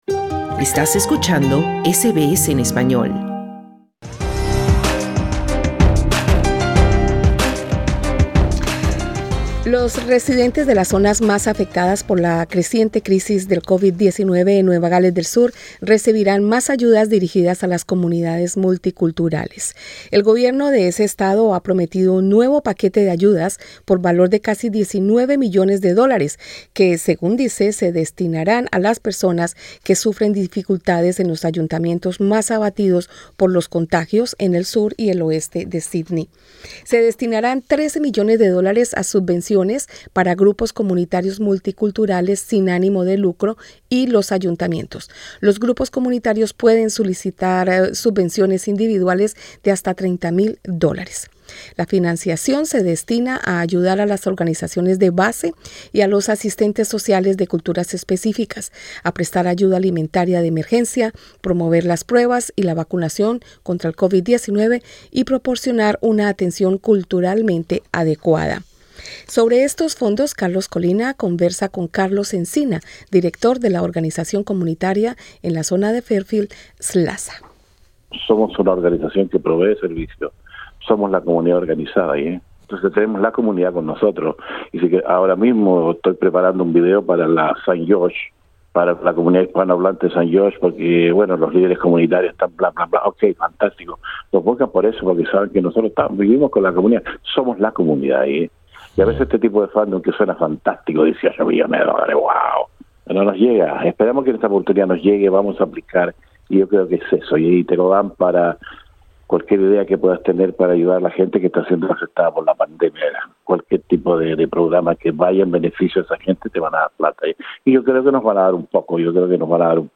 entrevista con SBS Spanish